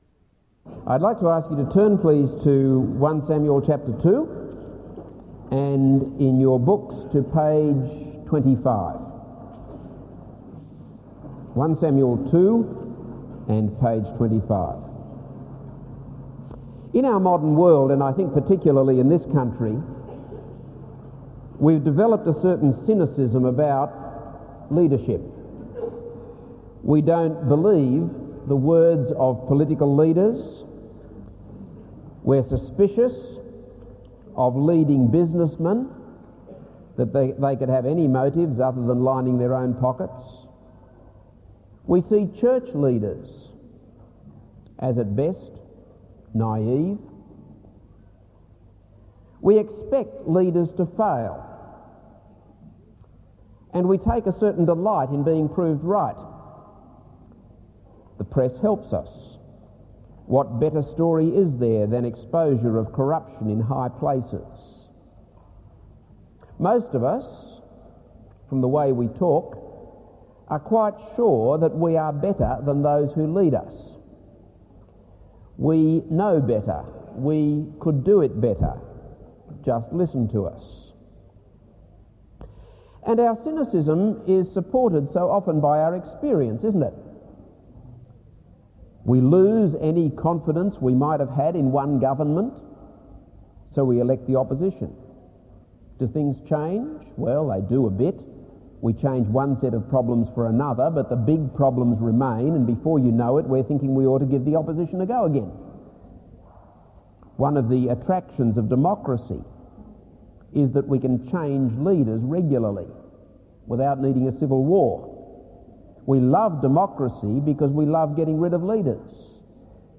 This is a sermon on 1 Samuel 2-7.